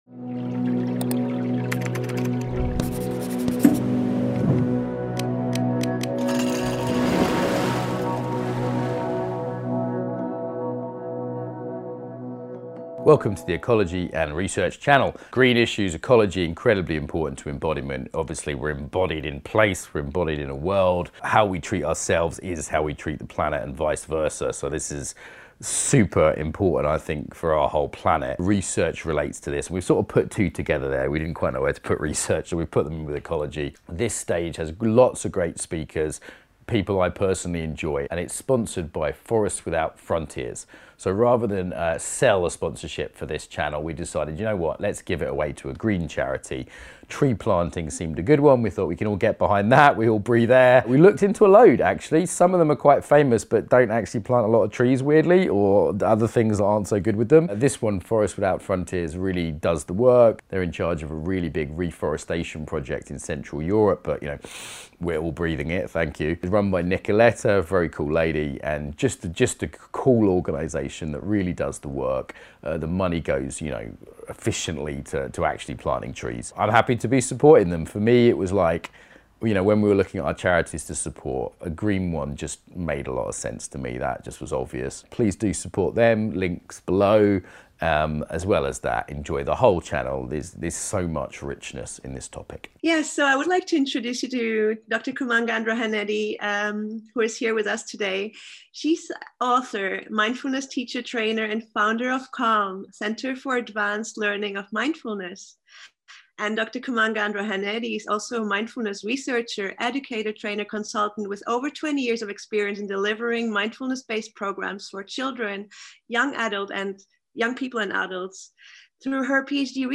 The session will provide an overview of the Advanced Mindfulness-Based Practices (AMBP) Programme and its current applications. The session will involve a brief talk, presentation and guided visualisation practices that can be integrated within your meditation practice.